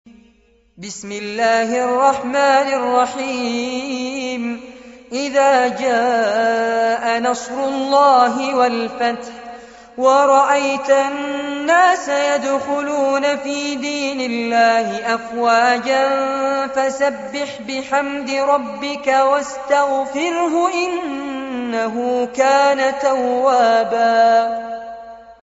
عنوان المادة سورة النصر- المصحف المرتل كاملاً لفضيلة الشيخ فارس عباد جودة عالية